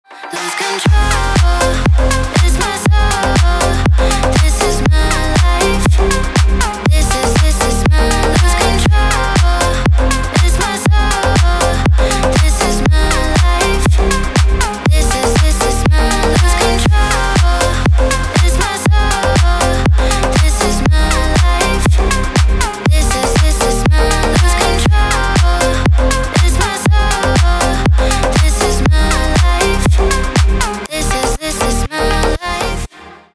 танцевальные
битовые , басы , крутые , качающие